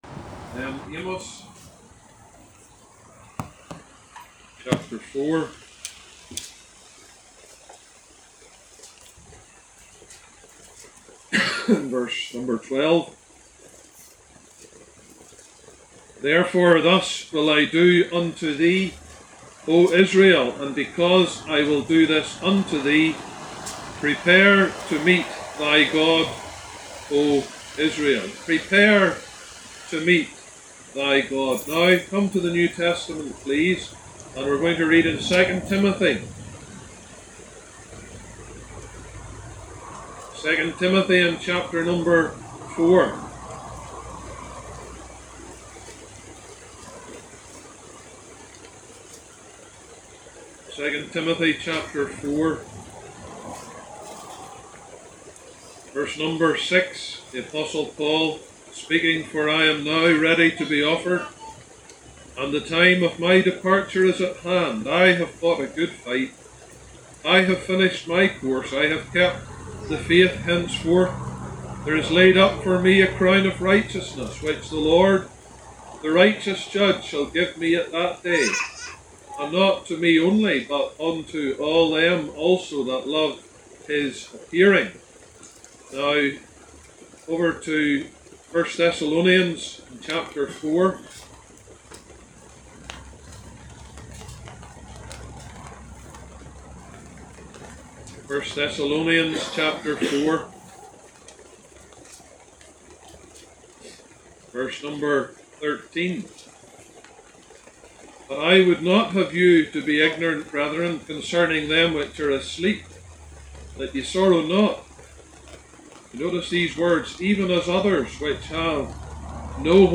2025 Gospel Tent